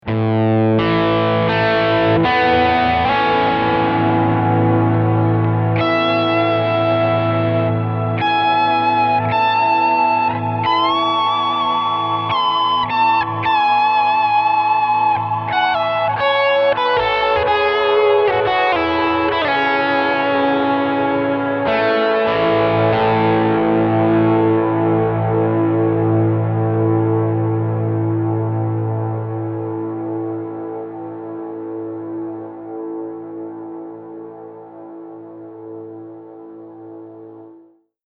The filter setting and long pre-delay allow for a soft and spacious pad that still has plenty of articulation. Here is what the BigSky random preset sounds like by itself: